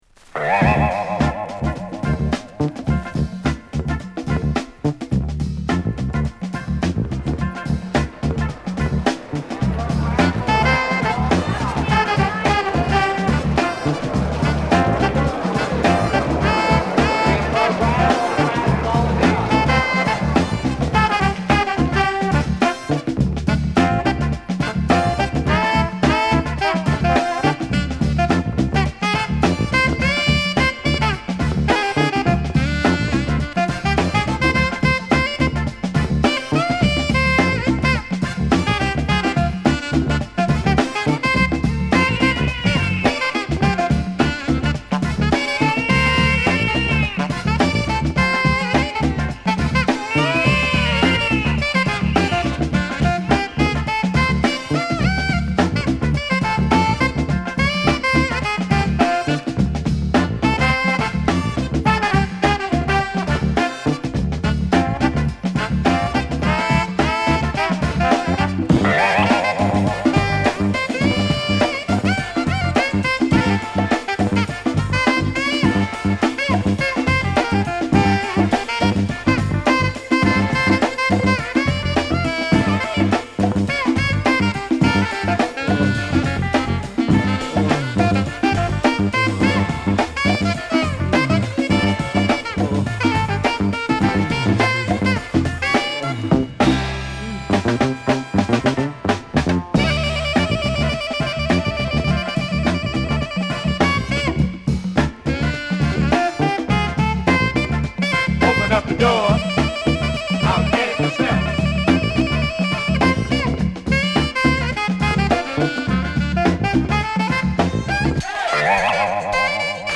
文句無しのファンク・クラシック！